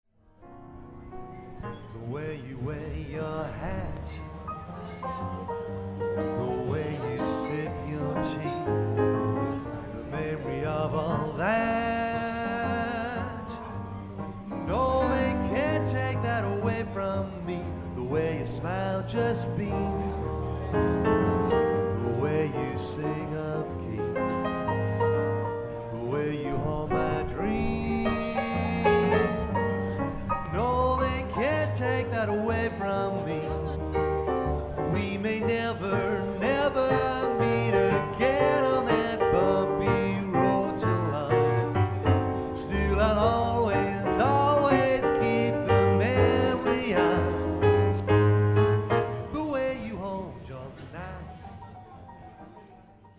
Photo:  Jazz singer
with vocals